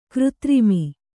♪ křtrimi